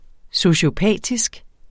Udtale [ soɕoˈpæˀdisg ]